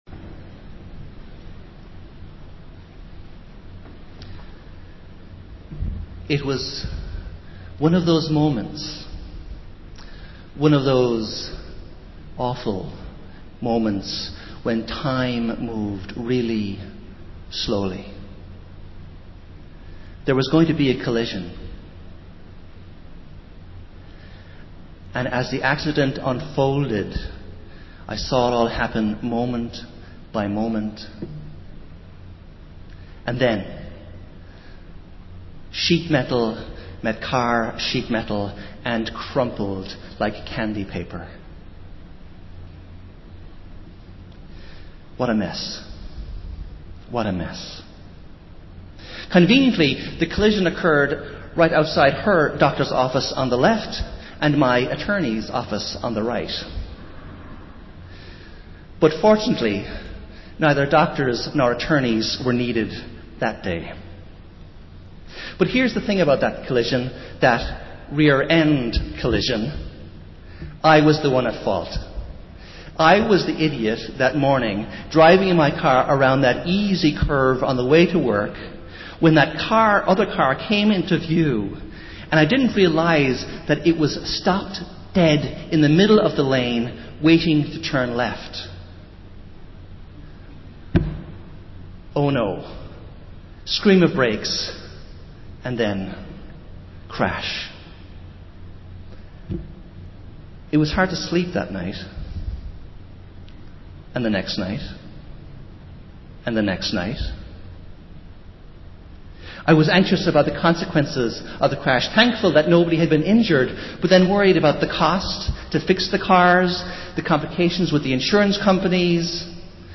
Festival Worship - Seventh Sunday after Pentecost